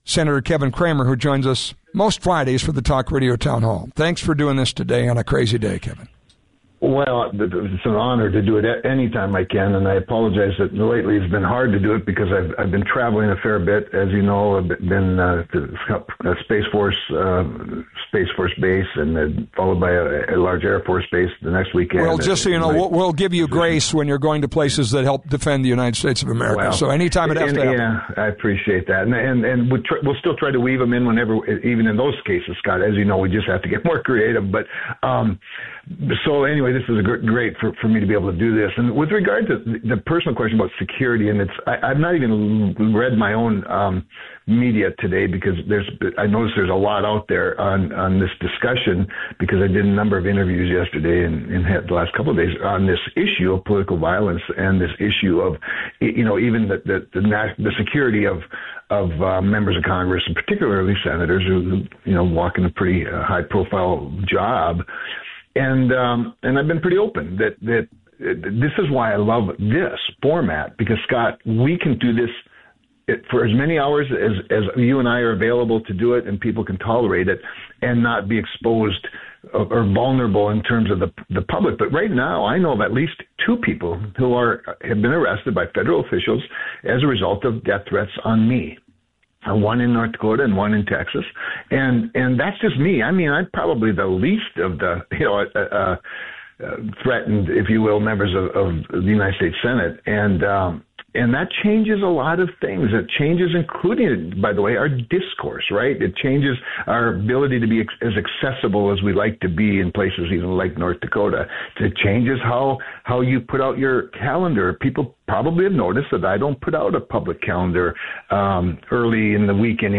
Listen: North Dakota Senator Kevin Cramer on ‘What’s On Your Mind?’